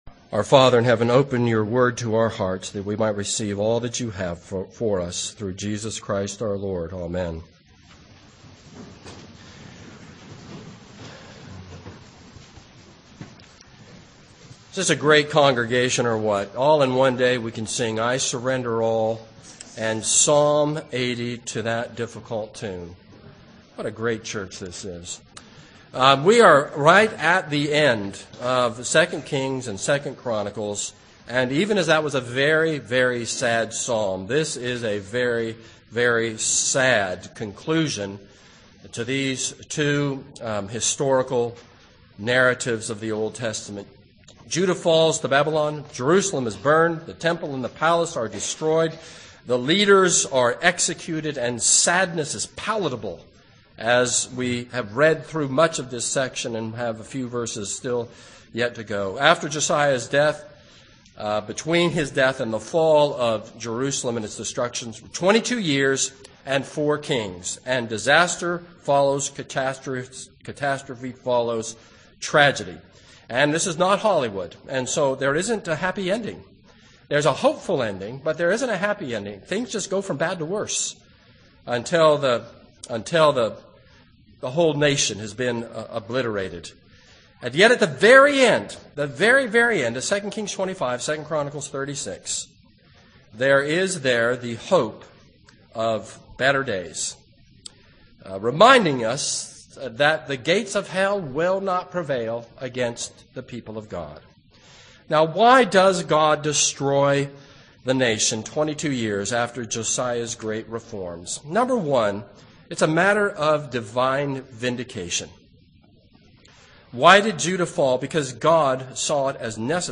This is a sermon on 2 Kings 23:31-25:30.